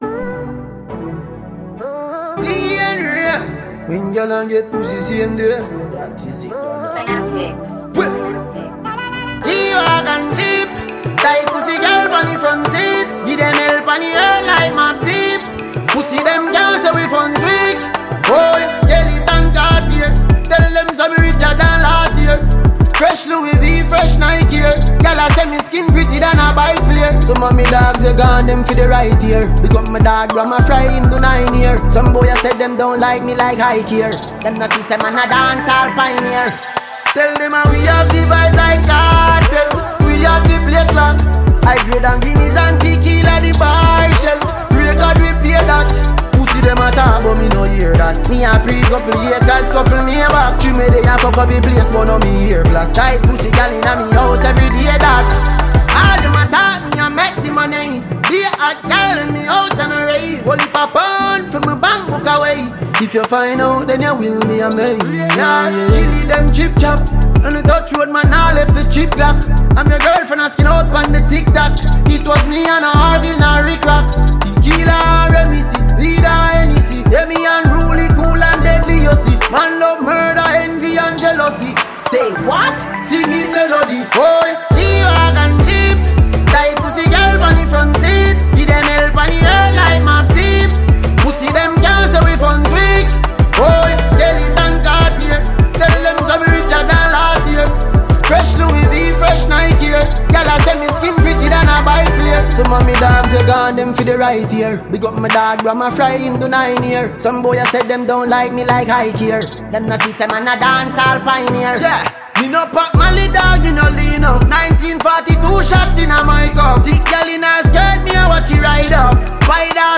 Jamaican dancehall